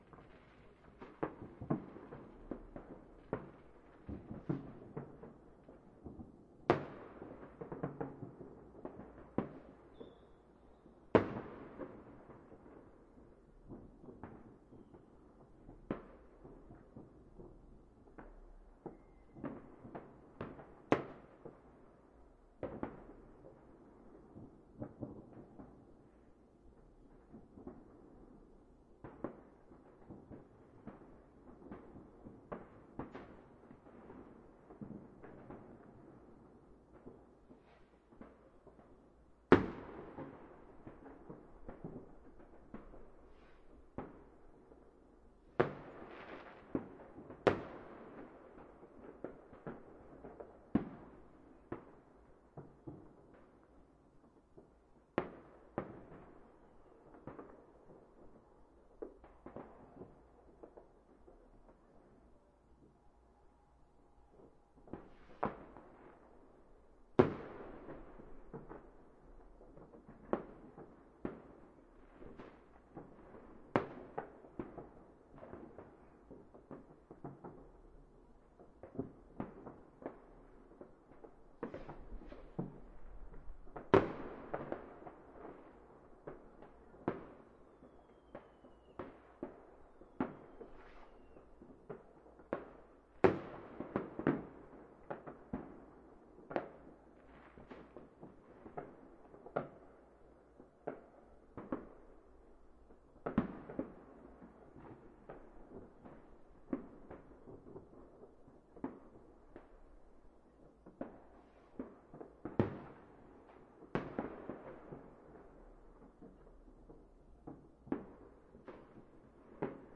烟花 " 才刚刚开始
描述：一位母亲告诉她的女儿，演出刚刚开始。我建议你用高质量的耳机听这个文件，因为它是由两个全向性的麦克风录制的。
标签： 遥远 现场录音 烟花 日本 天空 说话
声道立体声